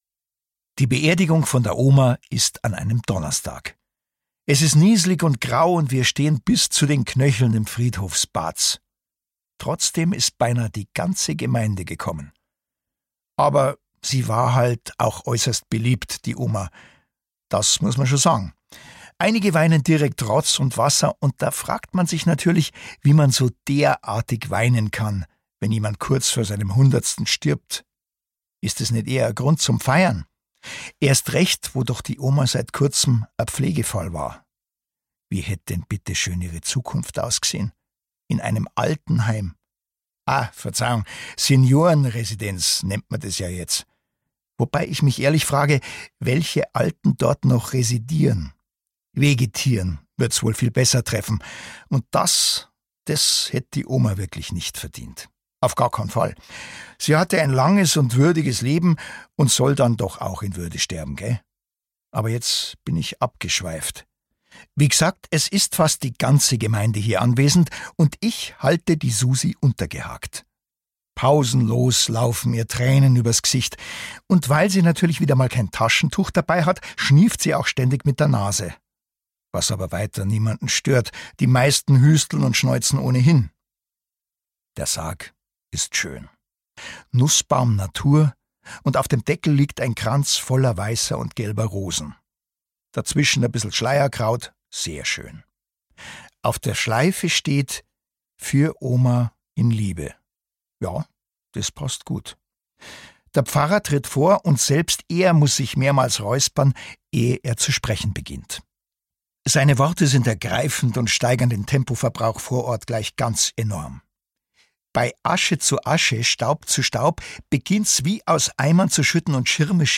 Die große Franz-Eberhofer-Box 2 Ungekürzte Lesungen mit Christian Tramitz
Christian Tramitz (Sprecher)